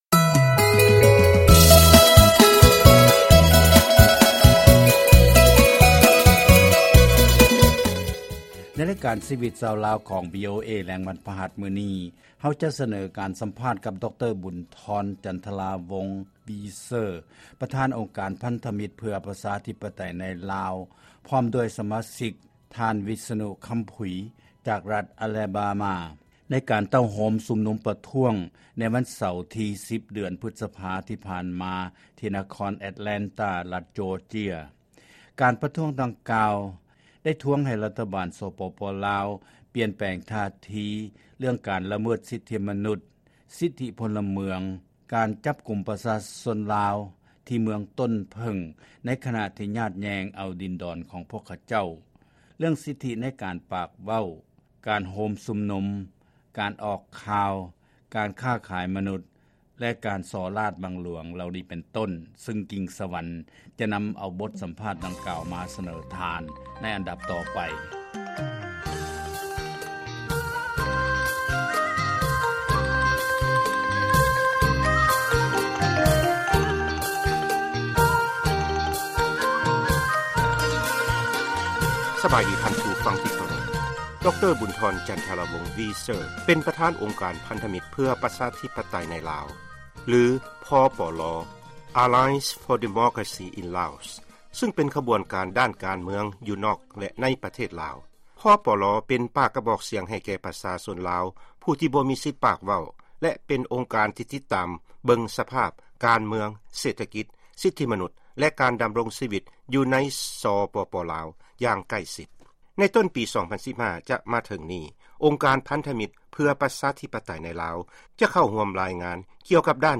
ຟັງການສຳພາດ ການເຕົ້າໂຮມ ອົງການພັນທະມິດ ເພື່ອປະຊາທິປະໄຕໃນລາວ